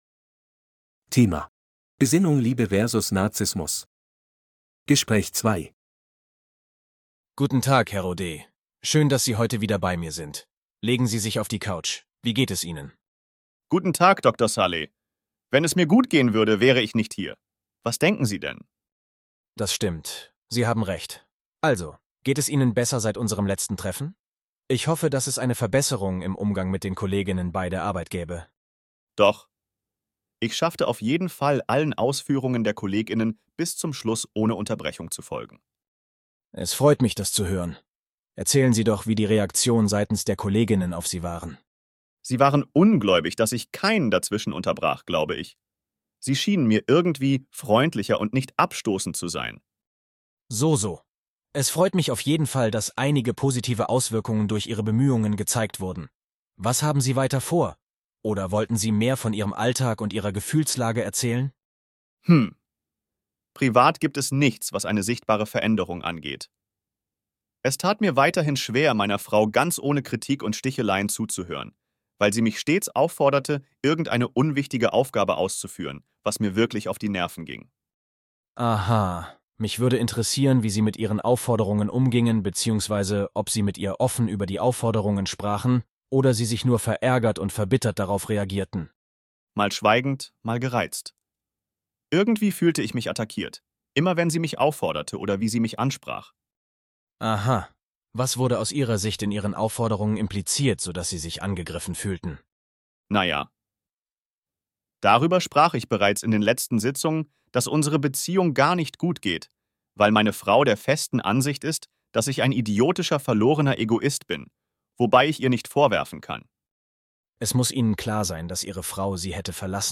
Bài nghe hội thoại 2:
B2-C1-Registeruebung-3-Besinnung-Liebe-vs.-Narzissmus-Gespraech-2.mp3